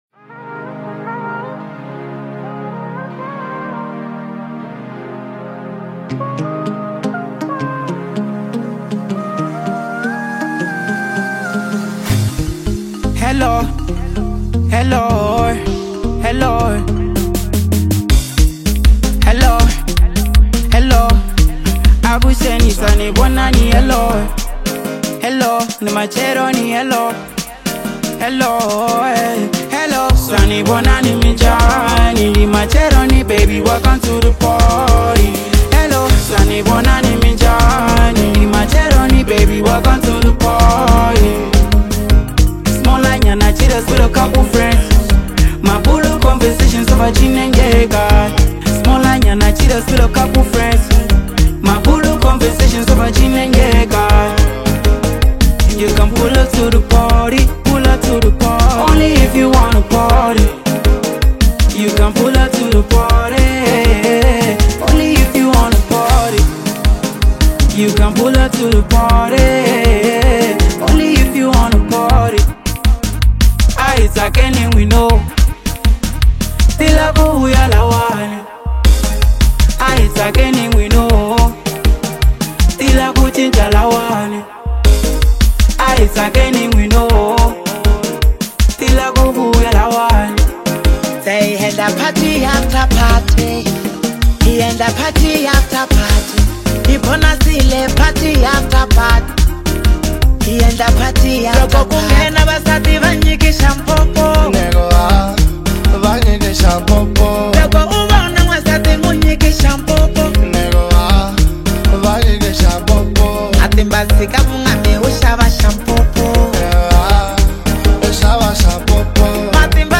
xitsonga